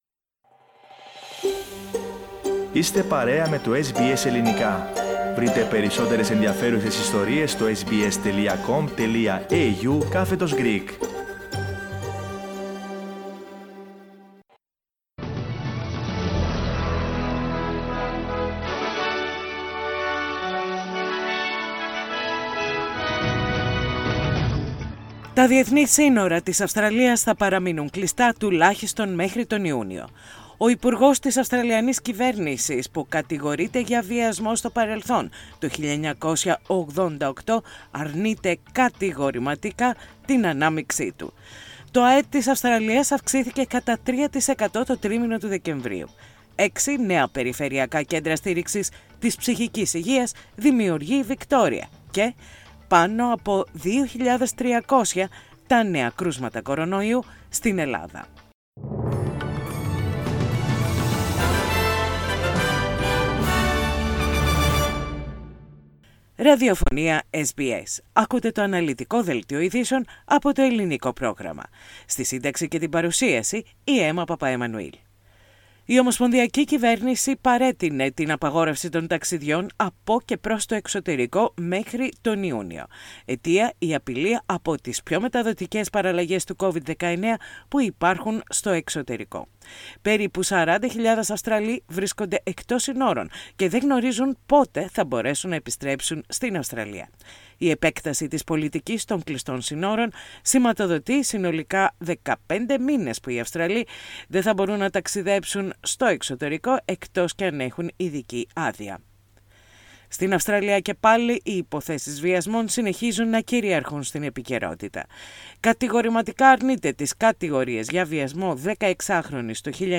Πατήστε play στο podcast που συνοδεύει την αρχική φωτογραφία για να ακούσετε το αναλυτικό δελτίο ειδήσεων, από το Ελληνικό πρόγραμμα της ραδιοφωνίας SBS.